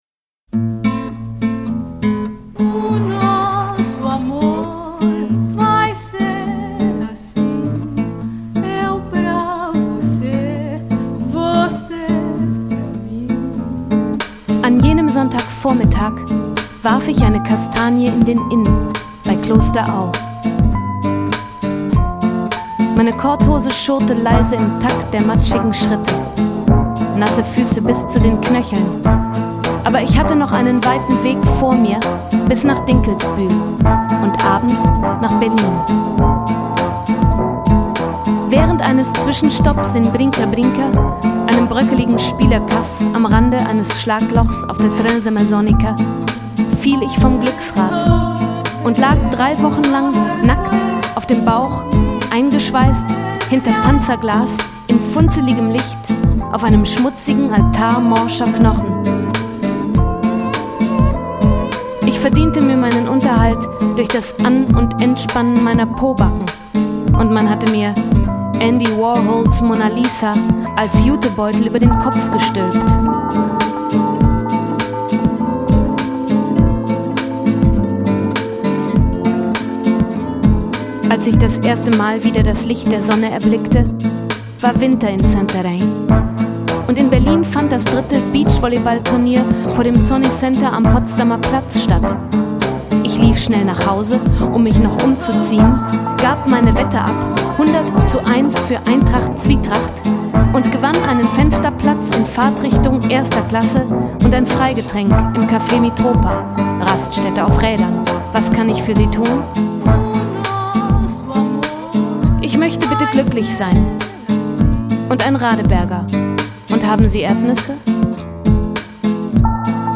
Genre: Gesprochene Popsongs